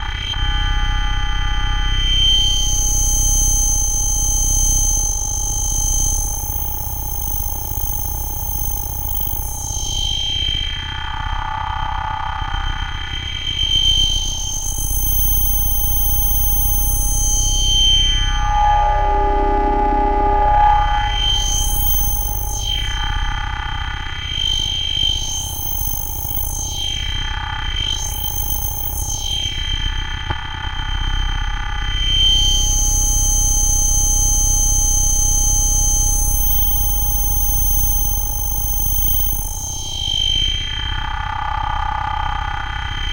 Звуки лазерного оружия
Писк уничтожения звуком лазерного оружия